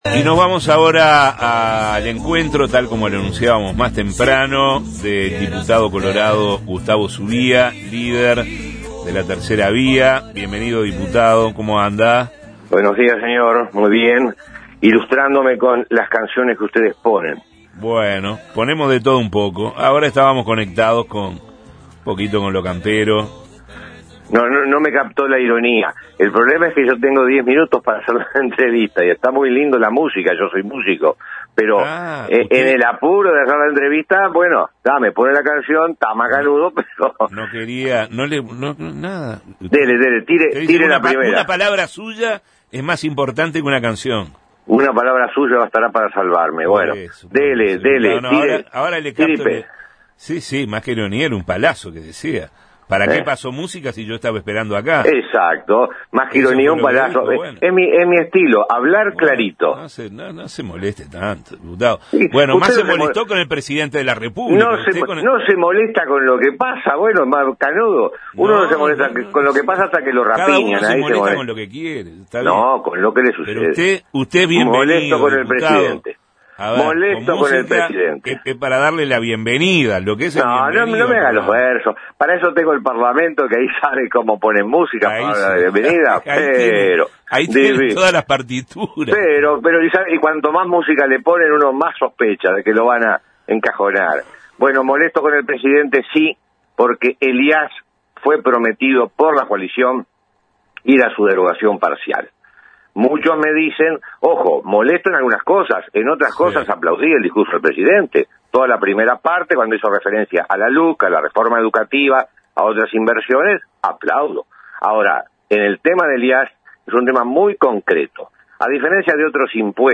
En Justos y Pecadores, el diputado colorado marcó posición sobre los anuncios del Presidente de la República ante la Asamblea General